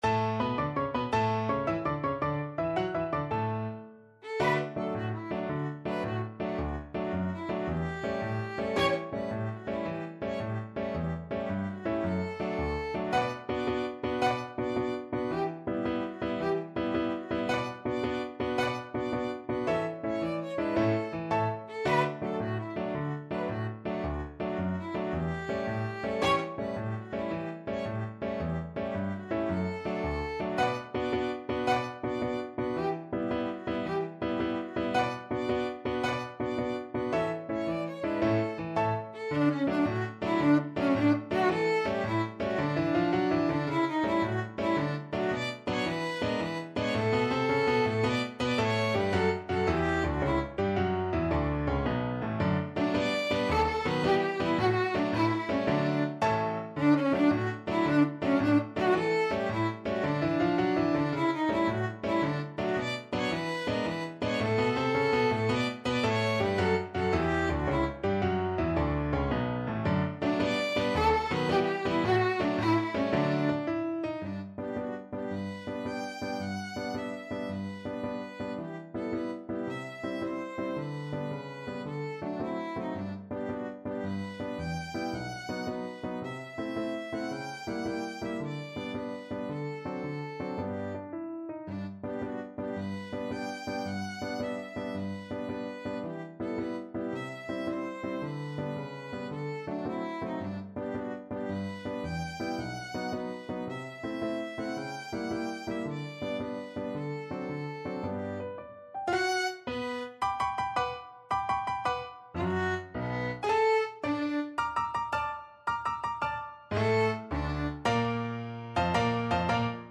Classical Sousa, John Philip King Cotton March Violin version
Violin
D major (Sounding Pitch) (View more D major Music for Violin )
6/8 (View more 6/8 Music)
A4-G6
KingCotton_VLN.mp3